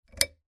Sound of a lamp lighting up
• Quality: High